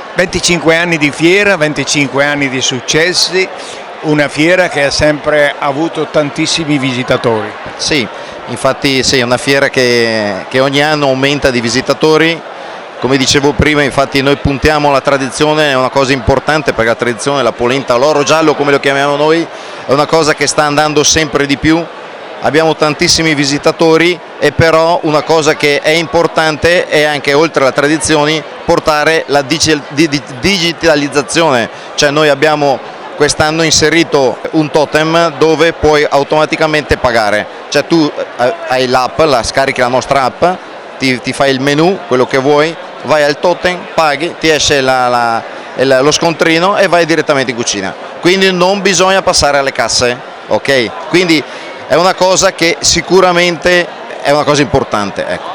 Al microfono del nostro corrispondente